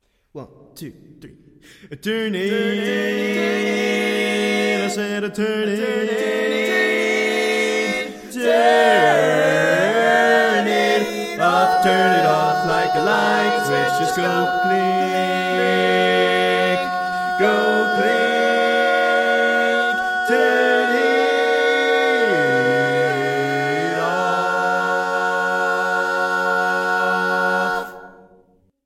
Key written in: G♭ Major
How many parts: 4
Type: Barbershop
All Parts mix: